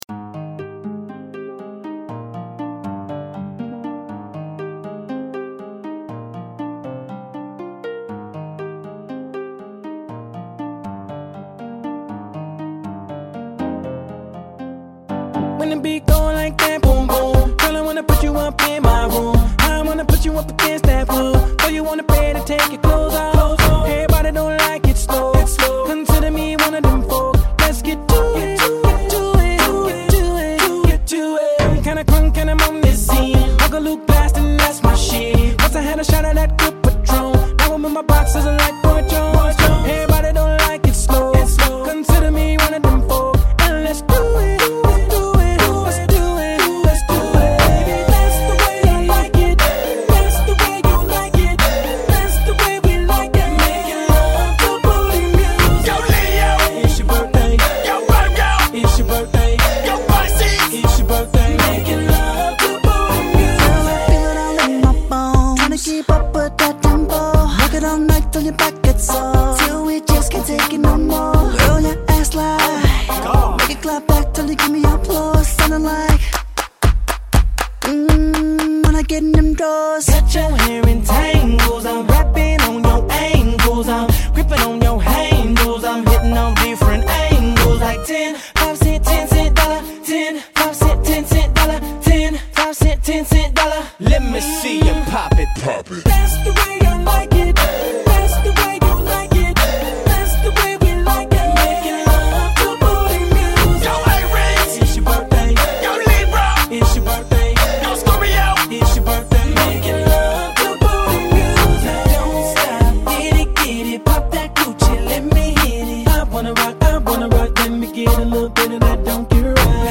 [28/10/2010]HOP-HIP曲